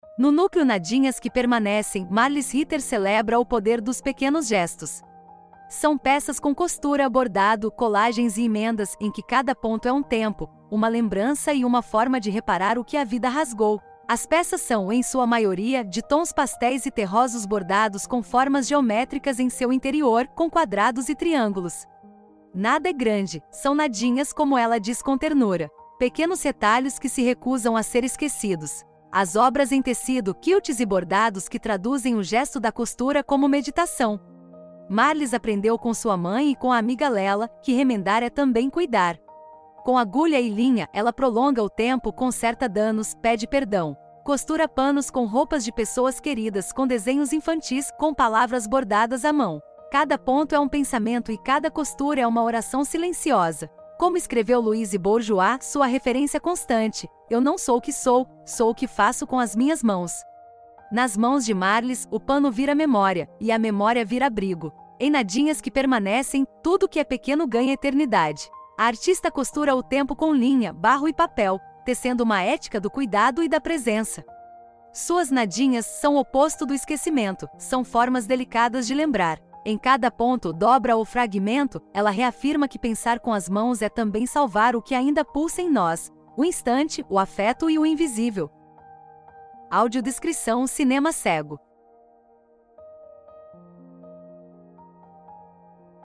Audiodescrição do Núcleo 4 | Nadinhas que Permanecem